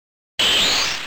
必杀技特效加音效 - 魔法特效 - 妖孽传奇素材网 - Powered by Discuz!